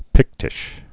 (pĭktĭsh)